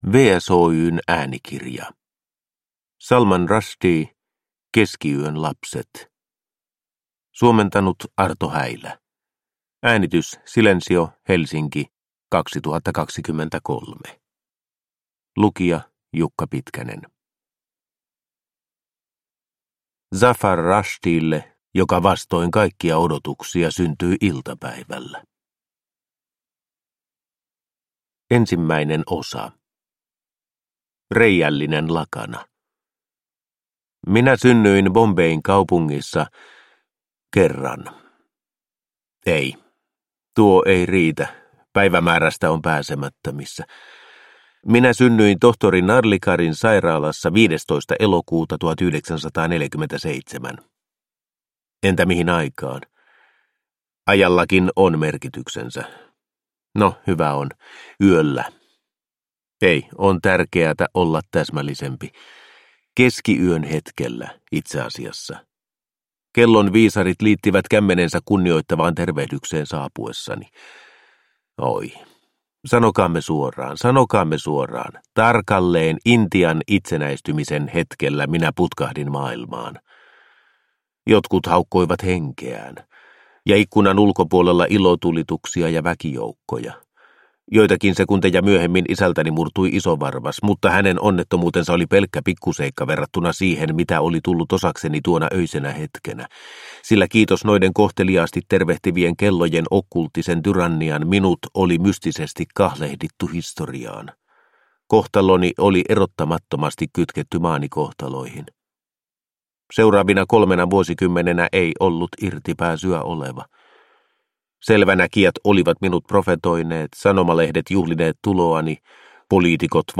Keskiyön lapset – Ljudbok